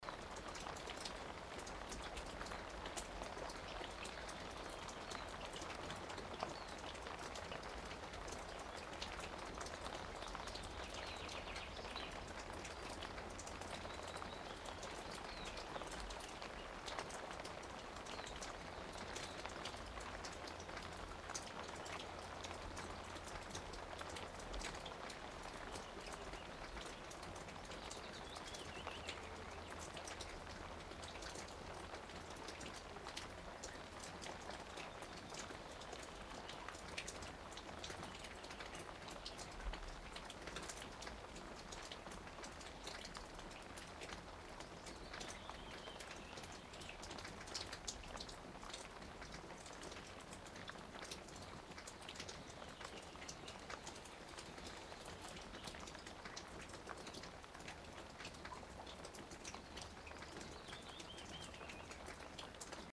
YLE (Finnish Broadcasting Company) recording archive.
24.100_finnish_soundscapes-rain_drops_on_a_shingle_roof.mp3